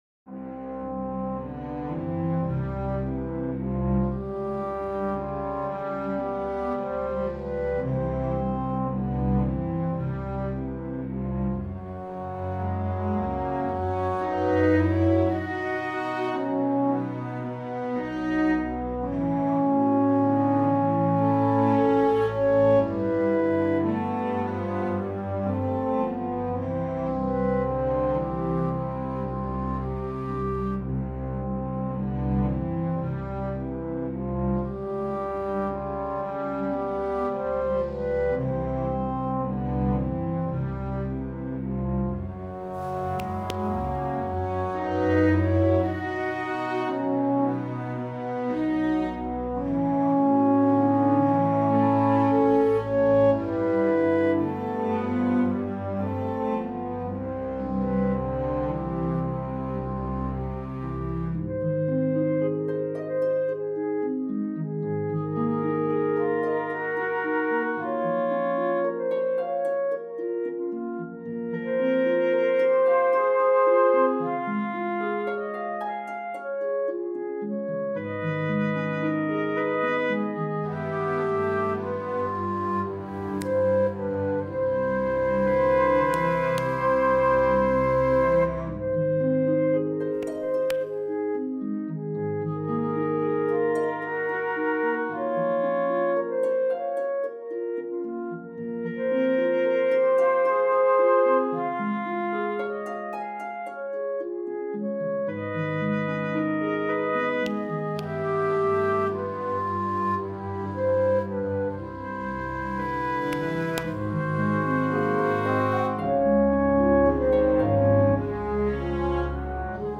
Performed by two intergenerational casts at:
Hear Interlude performed by the Jackal and Story Teller